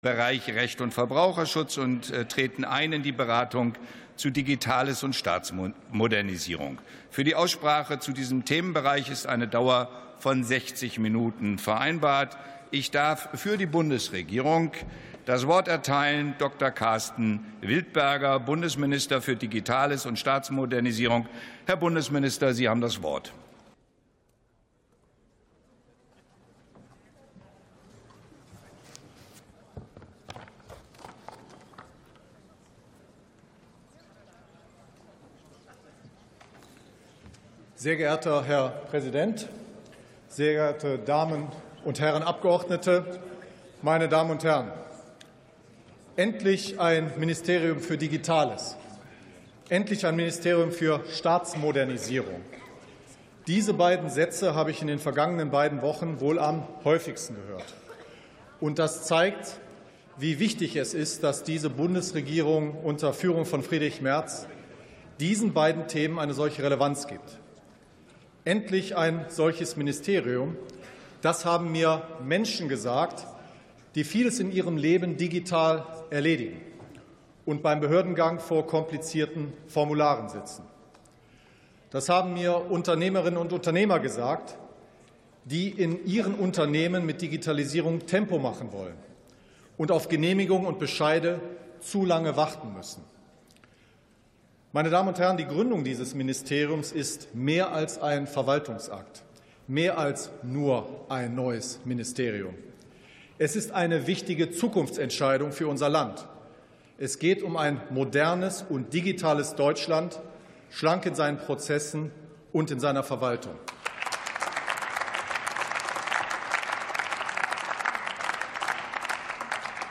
Plenarsitzungen - Audio Podcasts